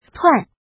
怎么读
tuàn
tuan4.mp3